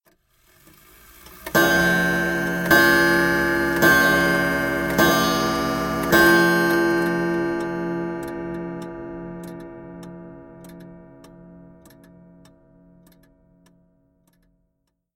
5 Chime
Tags: clock